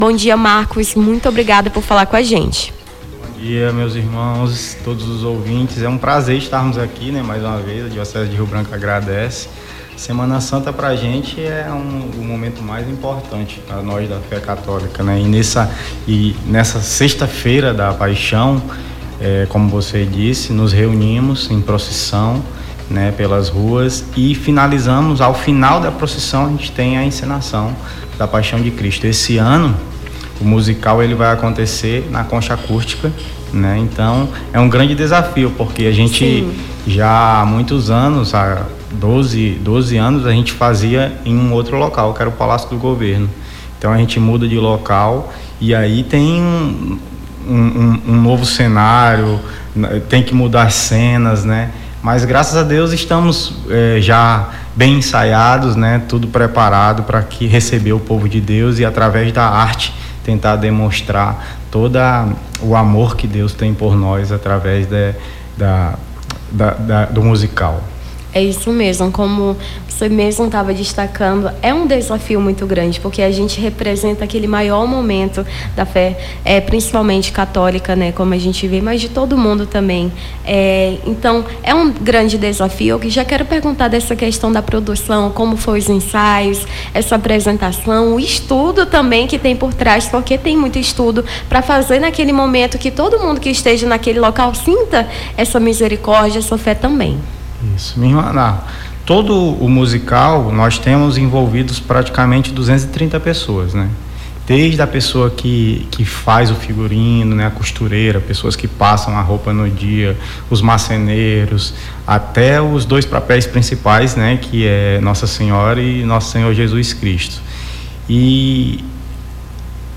Nome do Artista - CENSURA - ENTREVISTA (MUSICAL DA PAIXAO DE CRISTO) 17-04-25.mp3